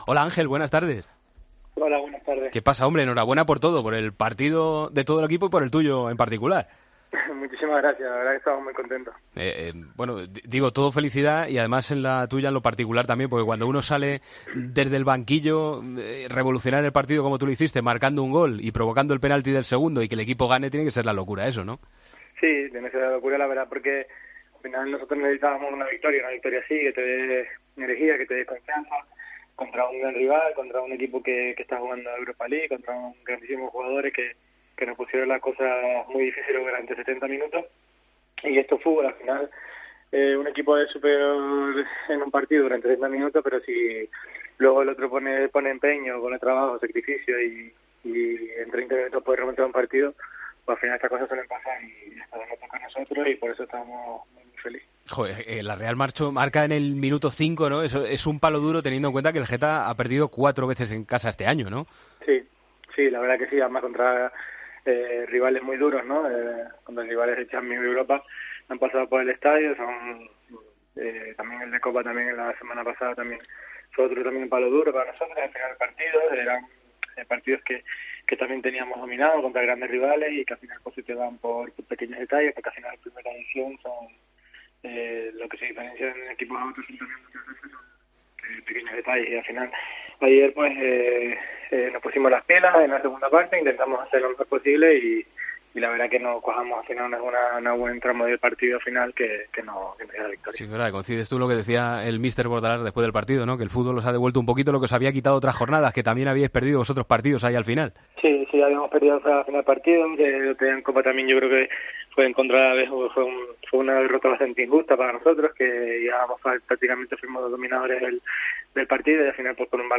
Hablamos con el jugador del Getafe, después de la victoria ante la Real Sociedad: "Nos pusimos las pilas en la segunda parte. Tenía ganas de ayudar al equipo a conseguir puntos. Vamos partido a partido, no miramos ni Europa ni el descenso".